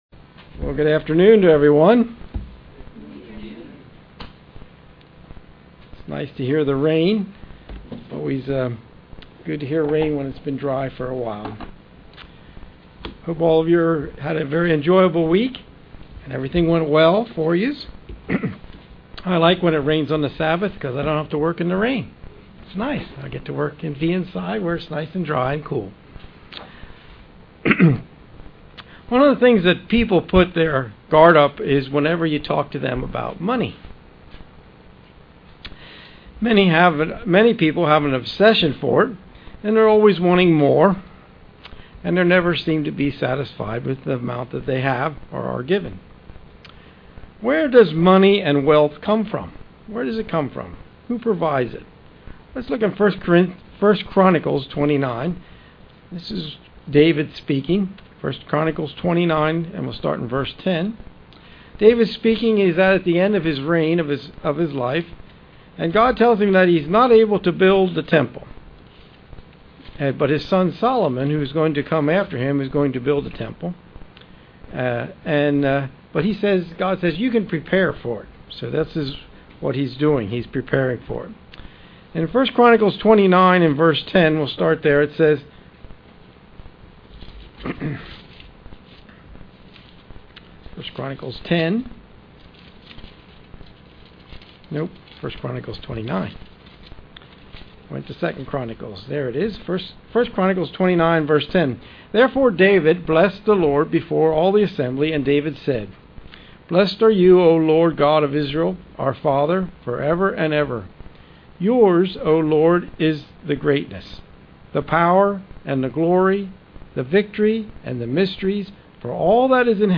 Given in York, PA
UCG Sermon Studying the bible?